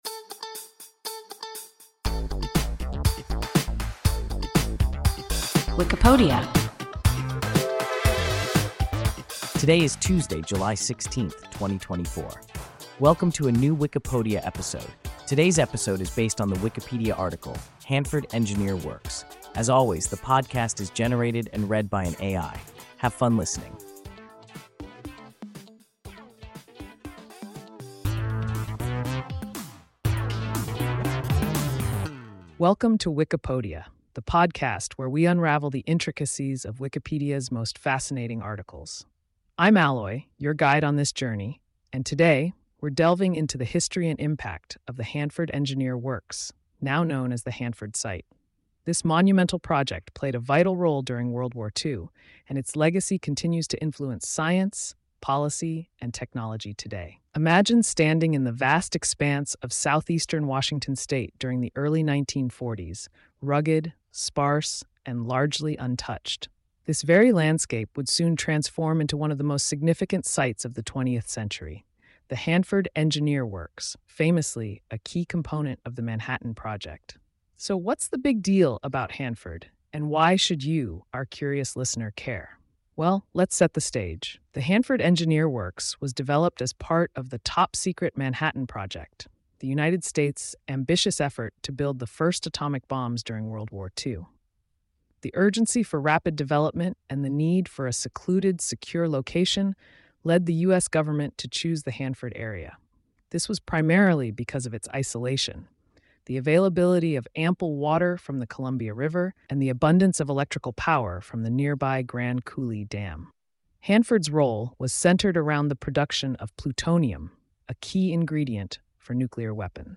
Hanford Engineer Works – WIKIPODIA – ein KI Podcast